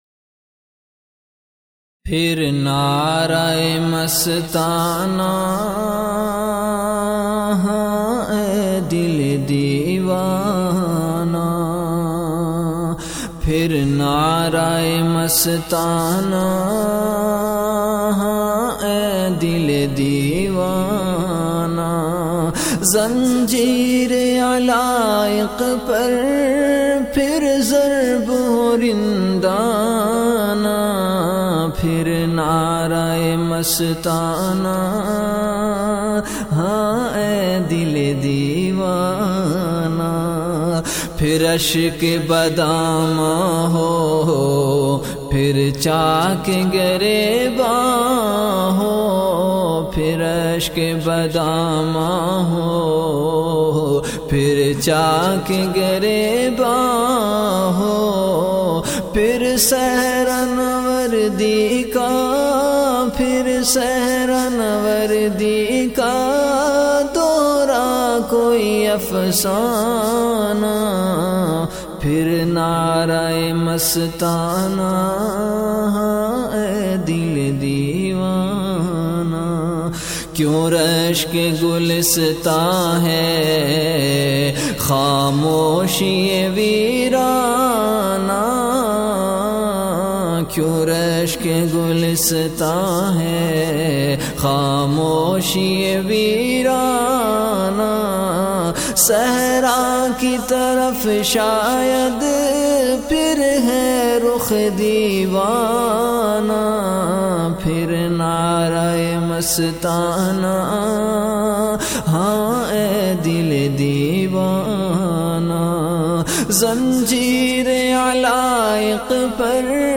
Ashaar · Khanqah Imdadia Ashrafia
CategoryAshaar
VenueKhanqah Imdadia Ashrafia
Event / TimeAfter Isha Prayer